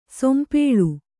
♪ sompēḷu